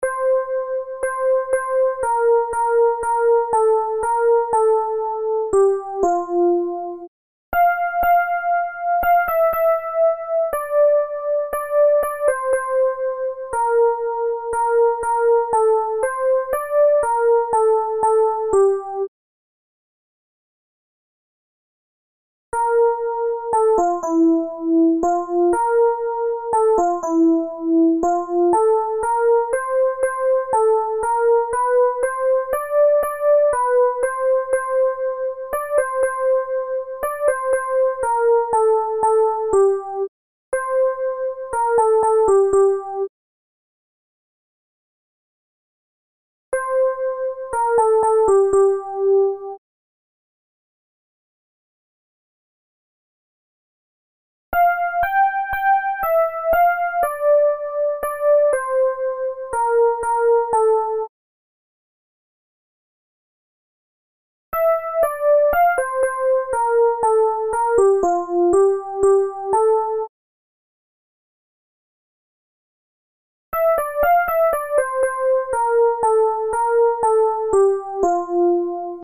Soprani
pres_du_tendre_soprani.MP3